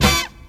horn_hit2.wav